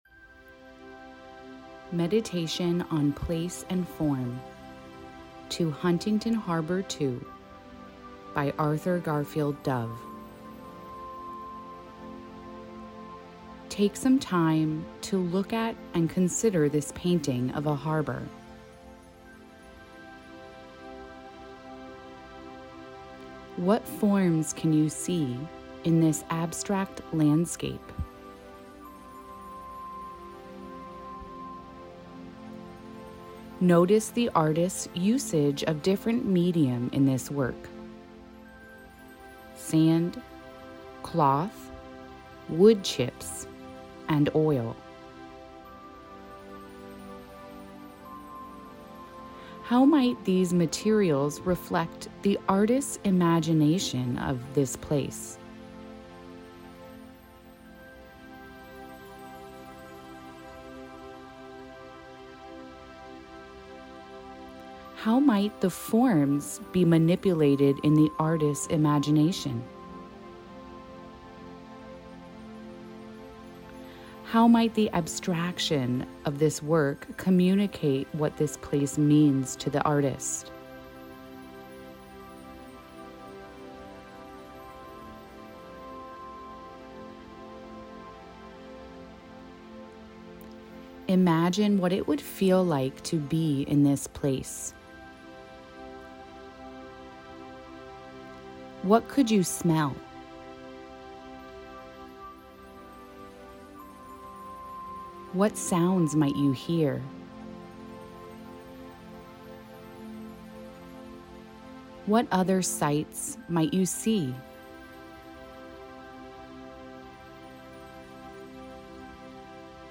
Make time for your mental health, and enjoy this guided audio meditation on thoughts inspired by Arthur Garfield Dove’s Huntington Harbor II.